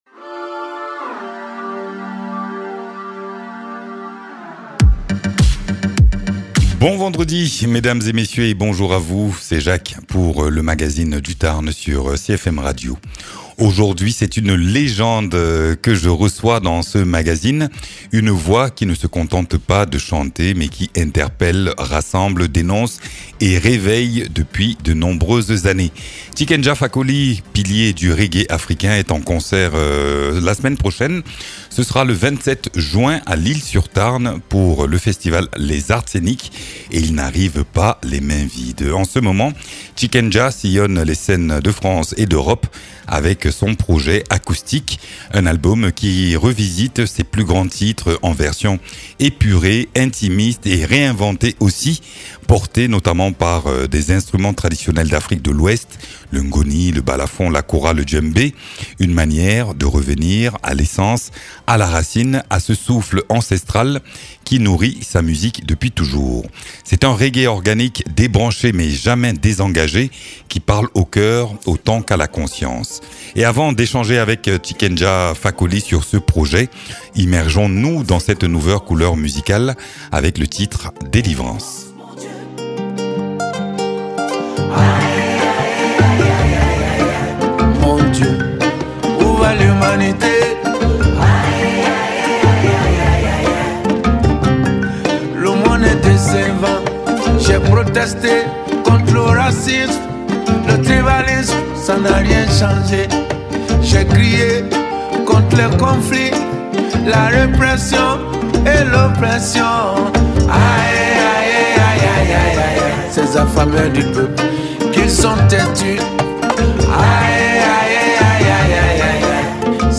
Invité(s) : Tiken Jah Fakoly, auteur-compositeur-interprète.